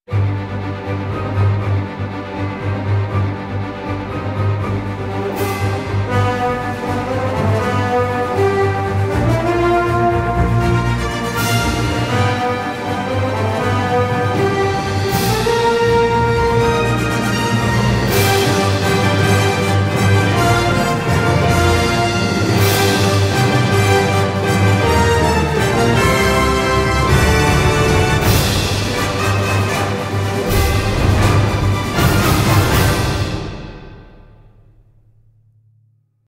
Soundtrack, Orchestral, Film Score